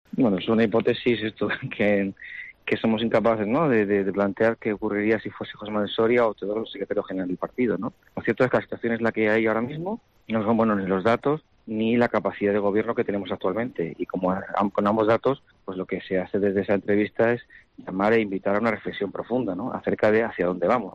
En una entrevista en COPE Canarias, Mariscal puntualizó que, como en cualquier empresa, se les evalúa por los resultados y estos actualmente "no son buenos".